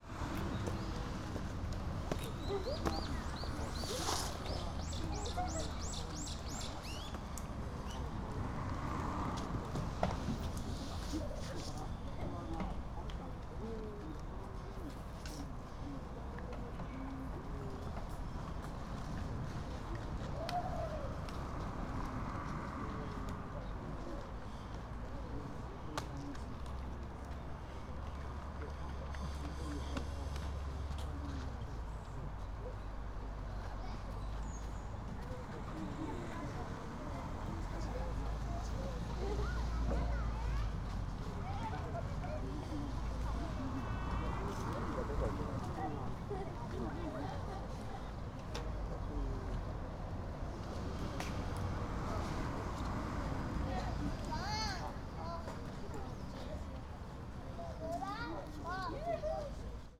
Fukushima Soundscape: Mt. Shinobu